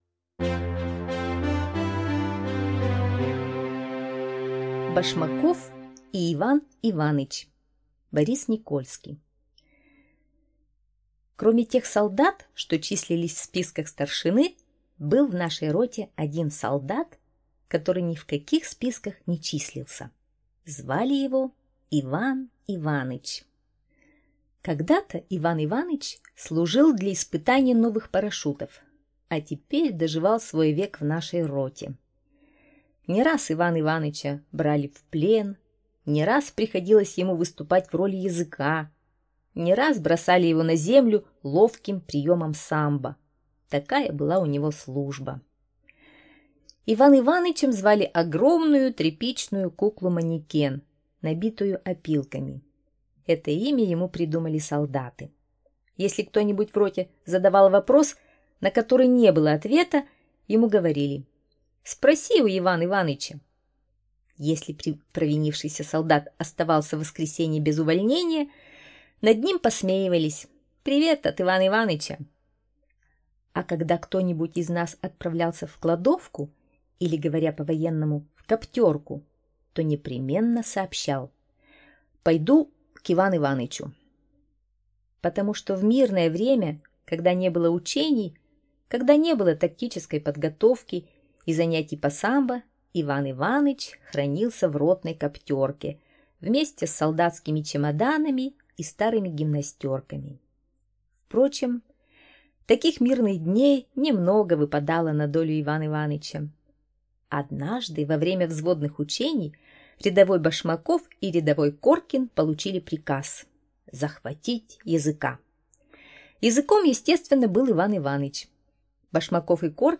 Аудиорассказ «Башмаков и Иван Иваныч»